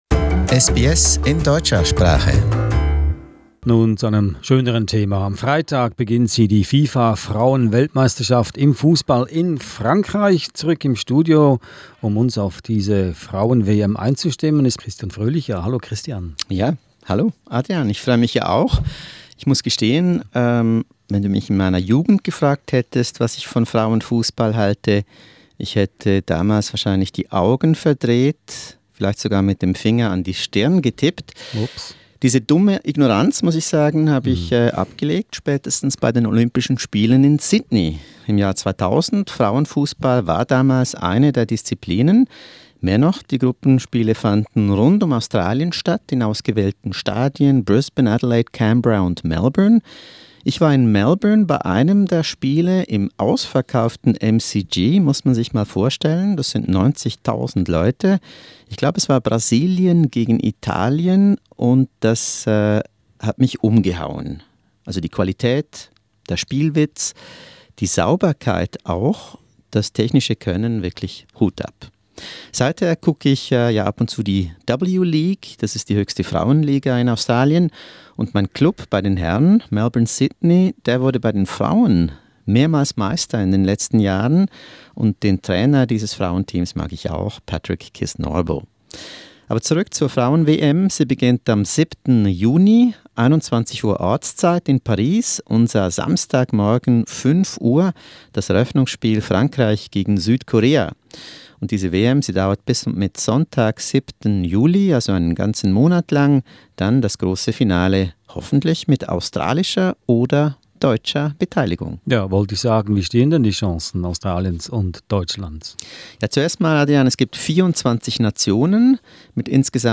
To tell us more about the 24 teams, especially Australia and Germany, listen to a SBS studio chat.